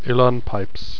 Uileann Pipes: pronounce " illan peips "